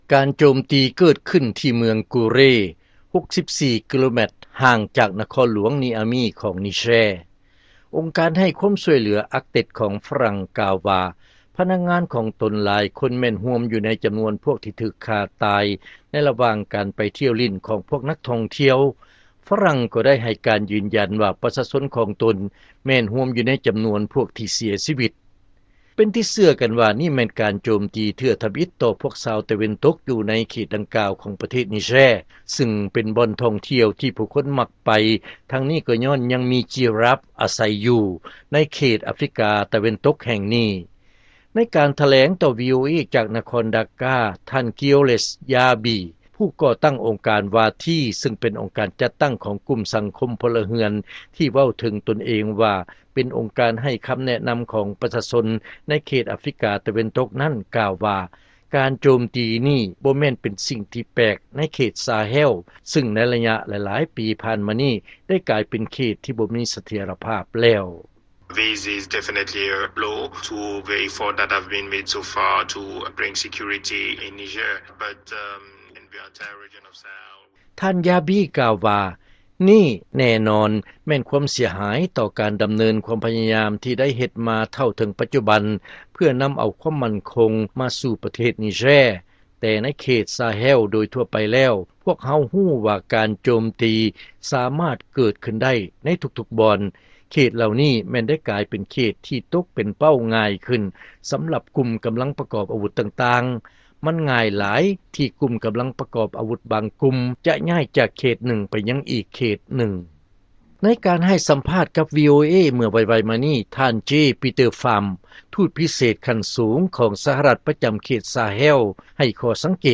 ເຊີນຟັງລາຍງານ ຝຣັ່ງປະນາມການໂຈມຕີ ຂອງພວກມືປືນ ຢູ່ປະເທດນິແຊຣ໌